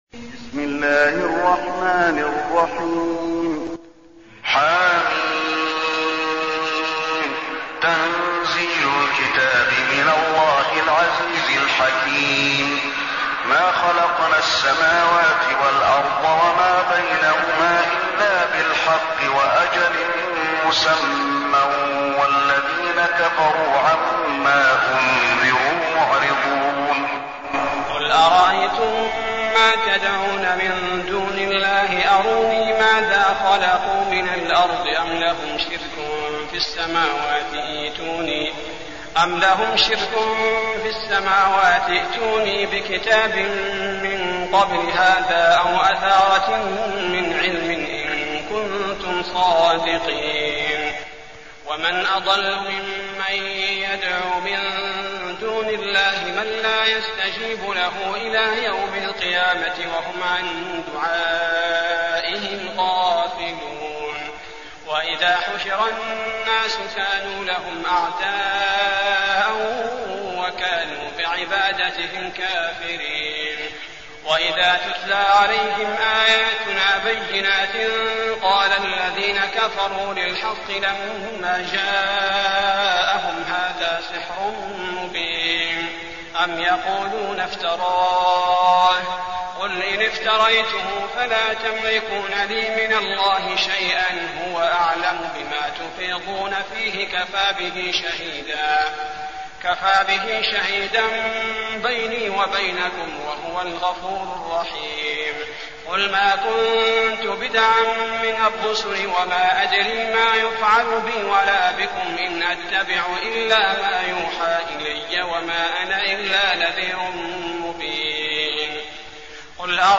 المكان: المسجد النبوي الأحقاف